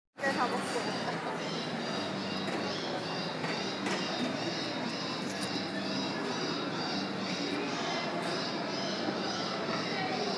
Screams of the piglets
Borough Market's recorded 'hawk' Designed to scare birds.. But sounds oddly like pigs in distress